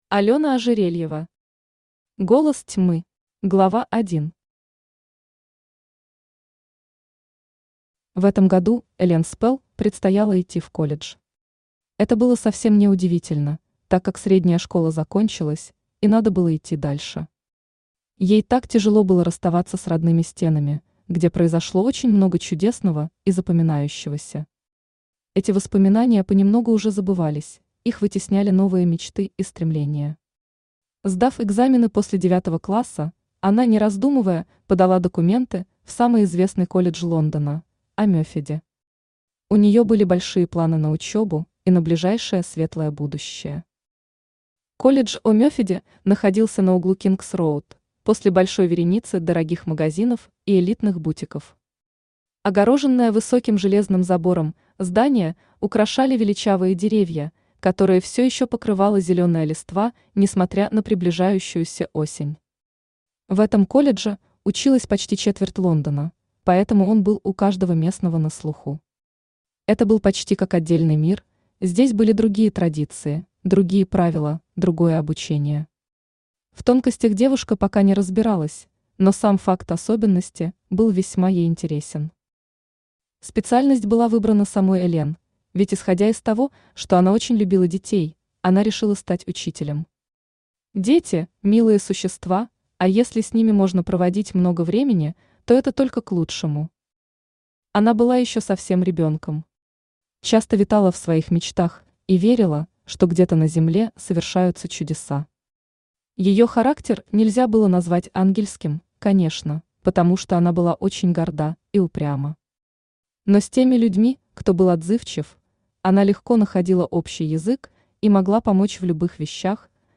Аудиокнига Голос тьмы | Библиотека аудиокниг
Aудиокнига Голос тьмы Автор Алёна Игоревна Ожерельева Читает аудиокнигу Авточтец ЛитРес.